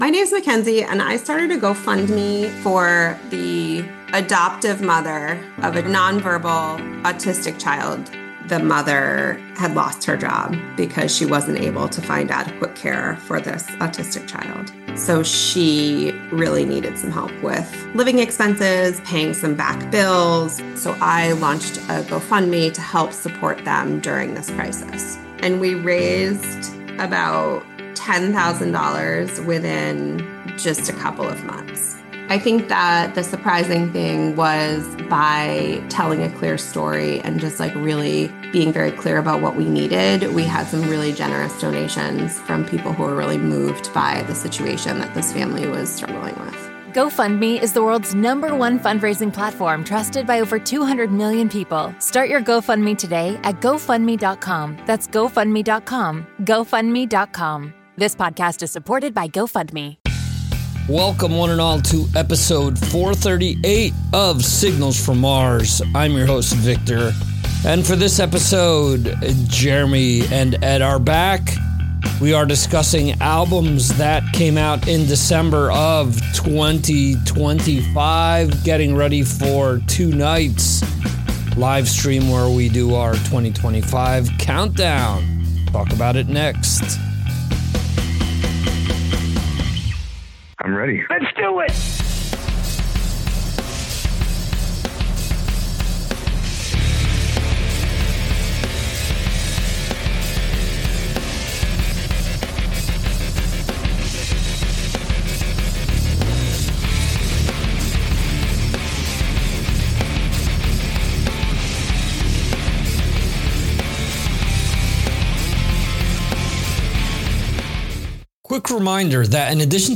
🎸 Signals From Mars – Episode 438 (Livestream Recording) 🎸
Expect debate, surprises, and plenty of passionate music talk!